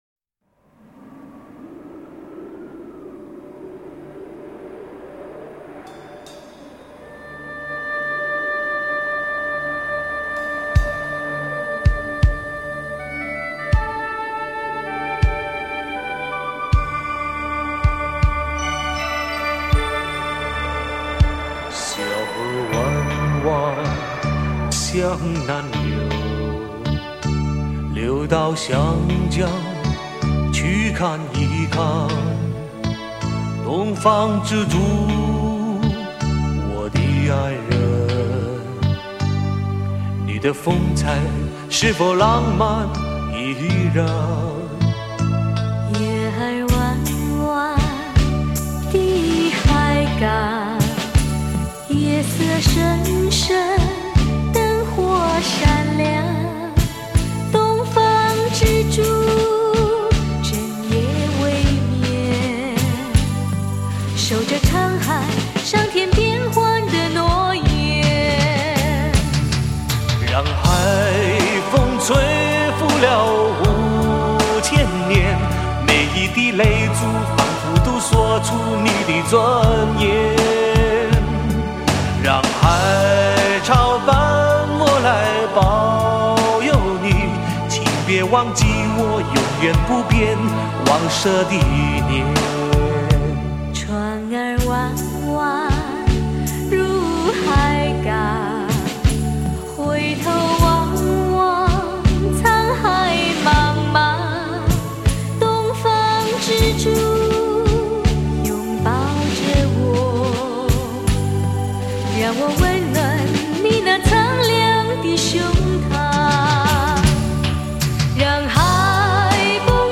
独一无二的魅力唱腔，蕴味浓郁的地方民族风情。